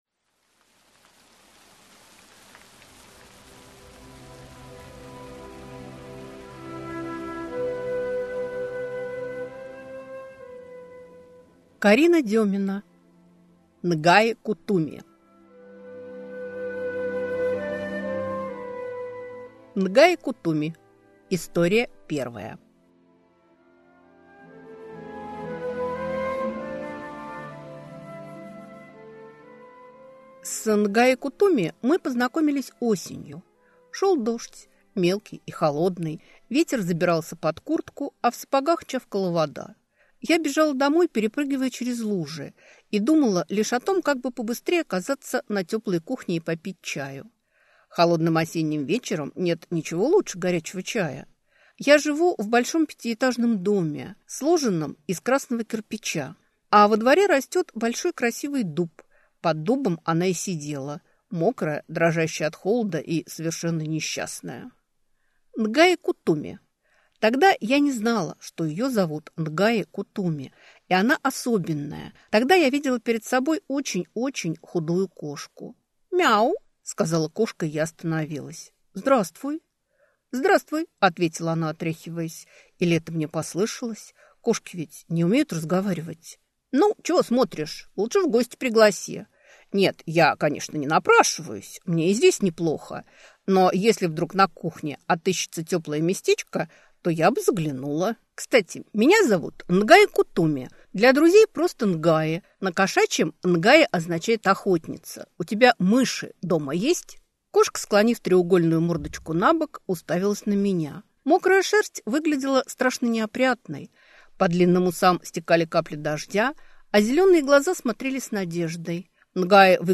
Аудиокнига Н'гаи-Кутуми | Библиотека аудиокниг